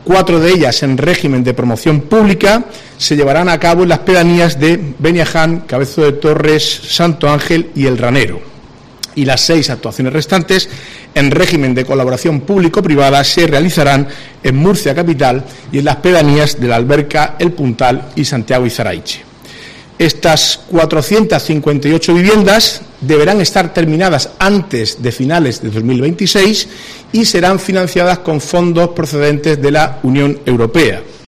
Marcos Ortuño, portavoz del Gobierno Regional